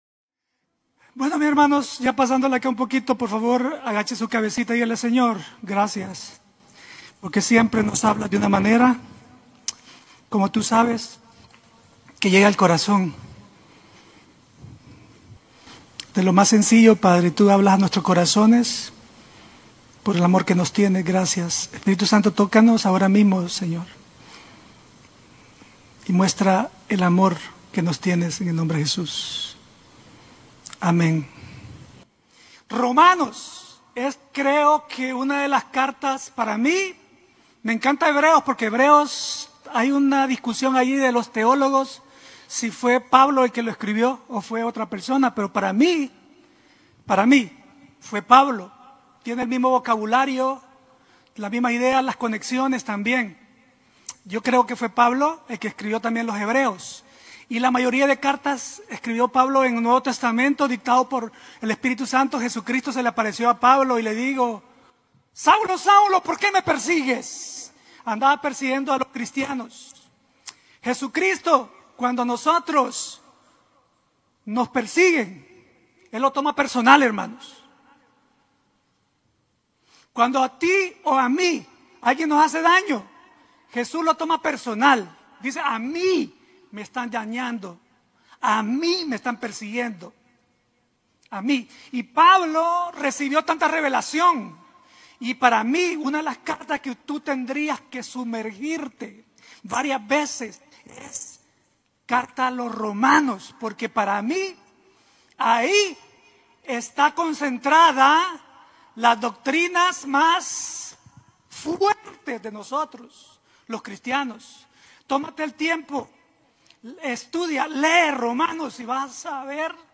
en unos de los tres servicios que transmiten de Chicago Illinois para el Mundo Hispano y Latino